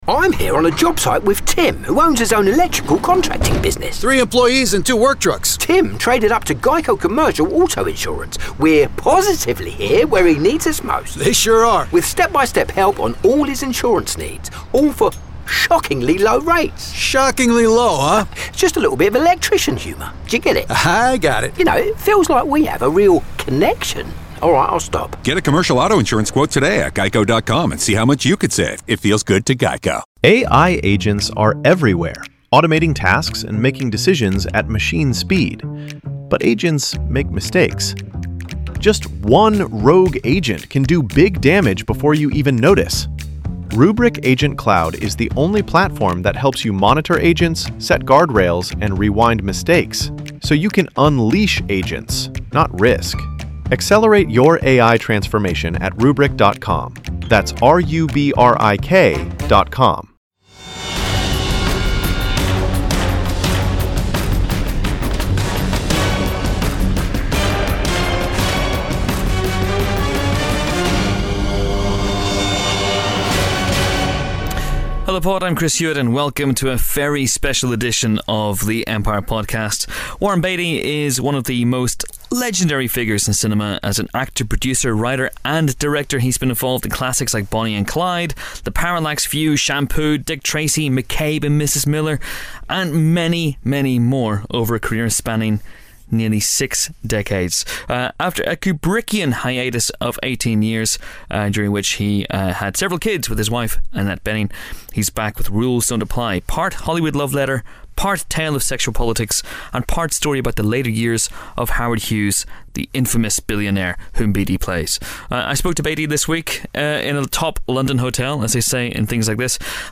A special hour-long interview with a genuine Hollywood legend. Expect anecdotes about Elia Kazan, Stanley Kubrick, David Lean, Ronald Reagan, and Elvis Presley.